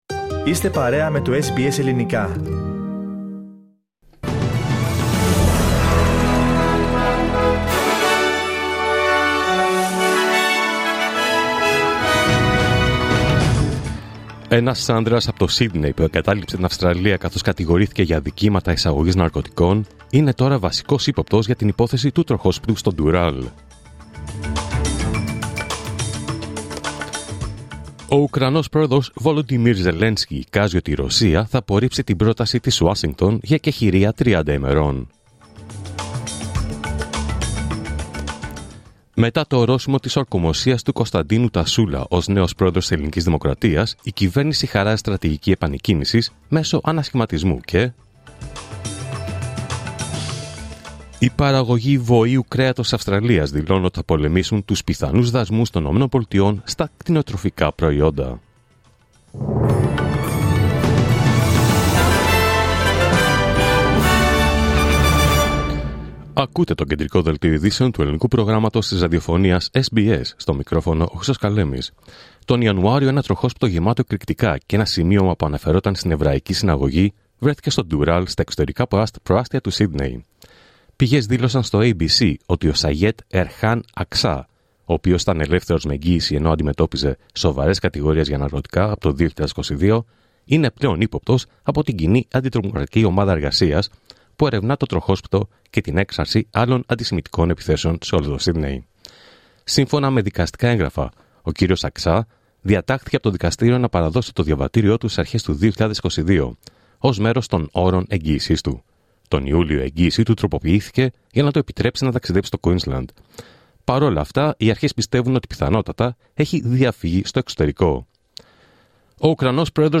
Δελτίο Ειδήσεων Παρασκευή 14 Μαρτίου 2025